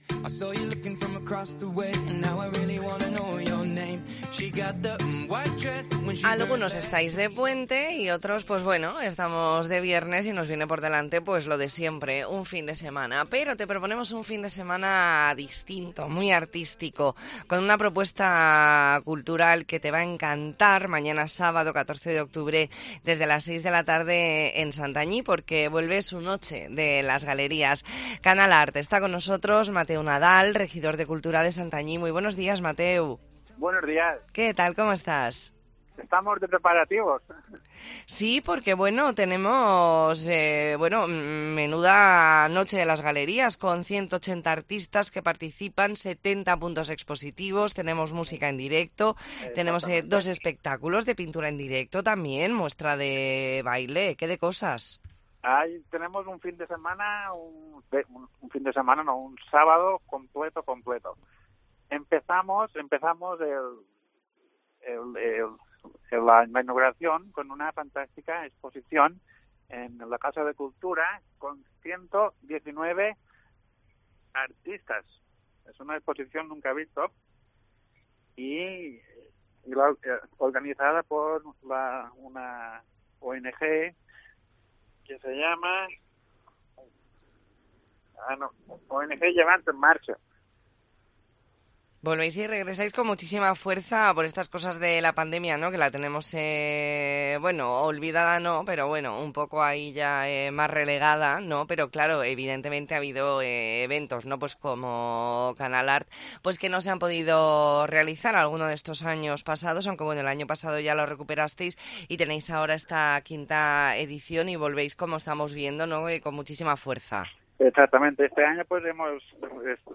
ntrevista en La Mañana en COPE Más Mallorca, viernes 13 de octubre de 2023.